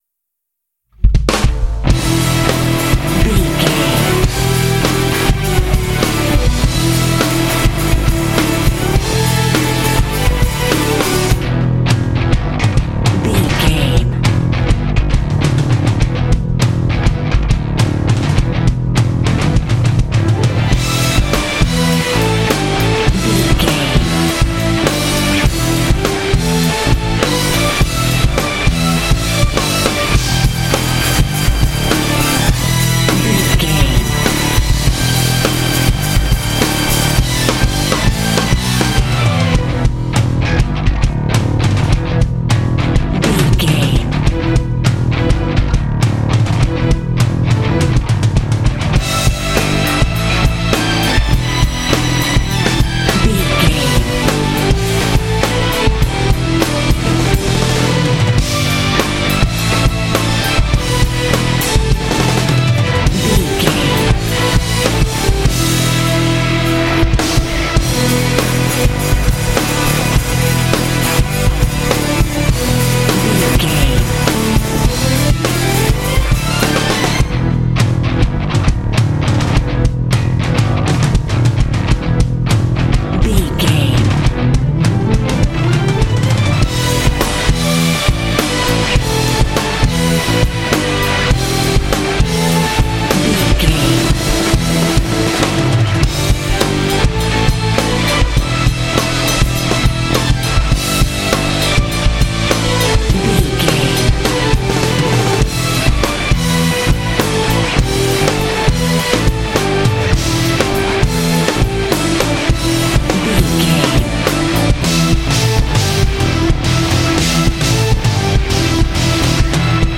Aeolian/Minor
powerful
energetic
heavy
bass guitar
electric guitar
drums
heavy metal
symphonic rock